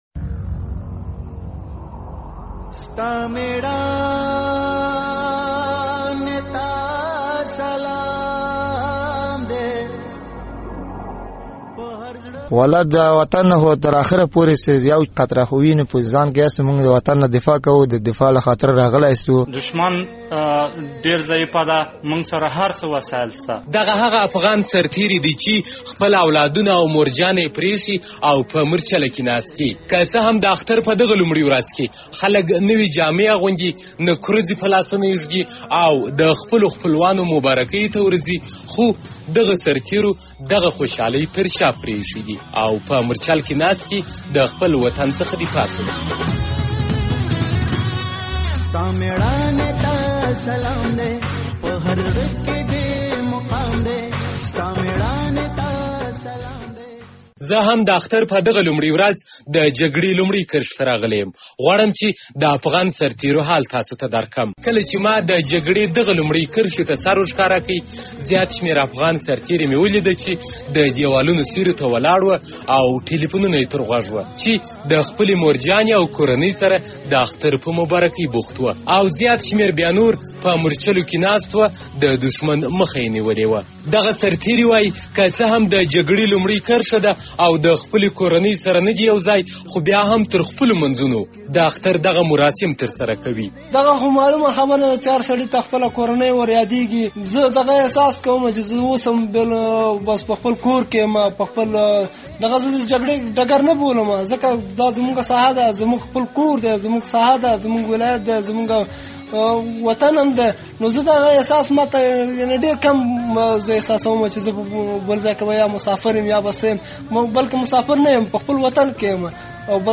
د ارزګان ولایت په مرکز ترینکوټ کې د لوی اختر په لومړۍ ورځ د جګړې په لومړۍ کرښه کې د افغان سرتېرو حال.